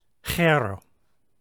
During the Koine period of Greek, the αι digraph sounded like the Greek letter Epsilon (ε) – like the English e in end. An English word that has a similar looking vowel combination and sound is the word “said”.